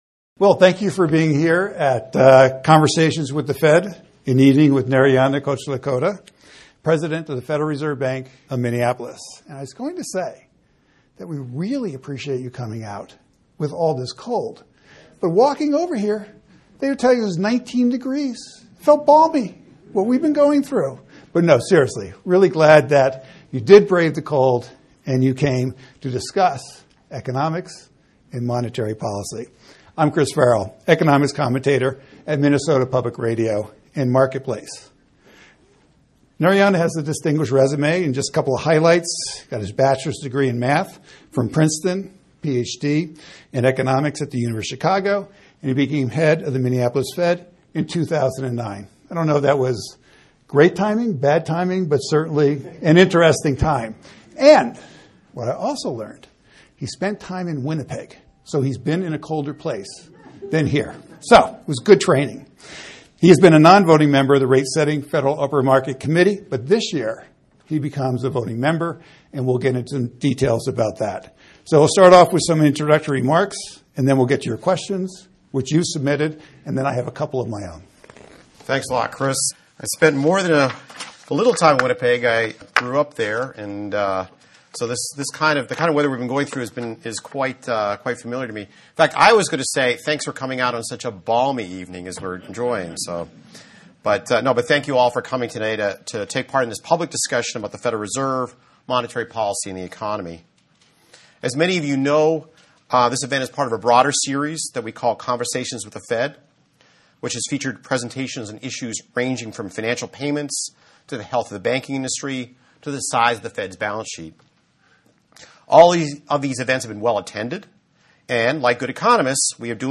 Speech (video) Q&A (video) Speech and QA (audio) Thank you all for coming out tonight to take part in this public discussion about the Federal Reserve, monetary policy and the economy.